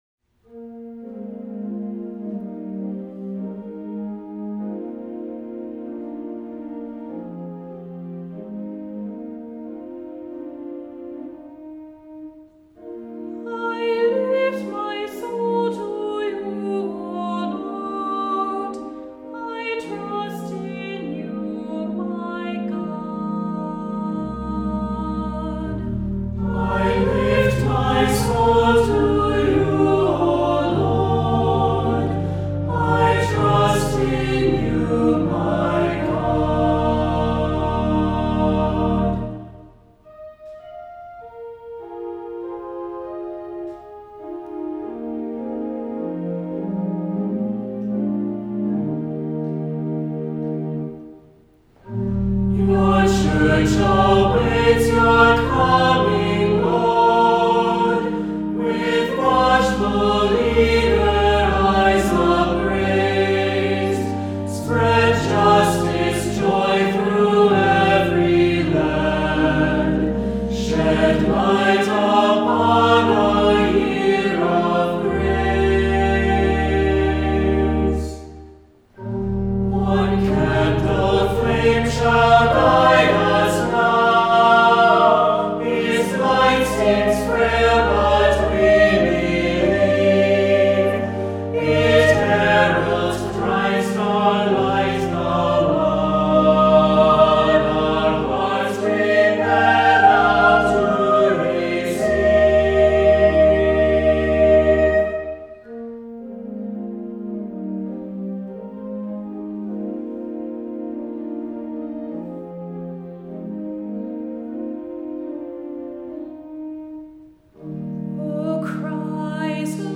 Voicing: SATB; Descant; Cantor; Presider; Assembly